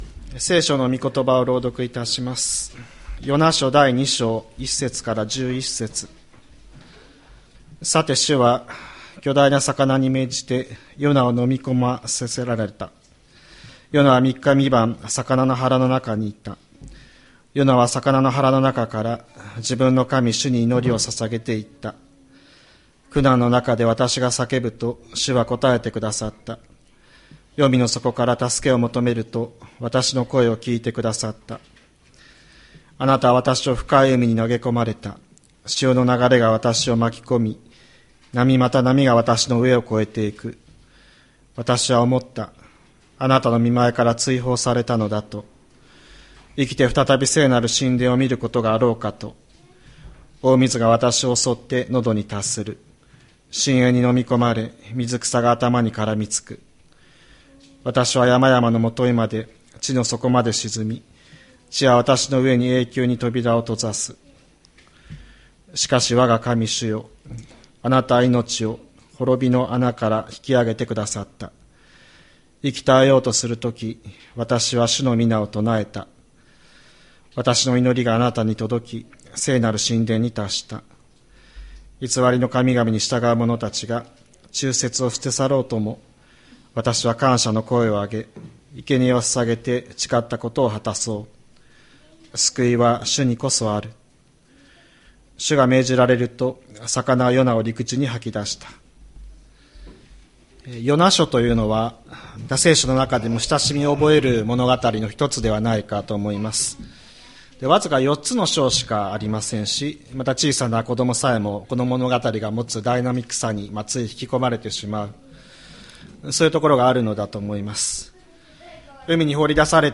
千里山教会 2025年02月23日の礼拝メッセージ。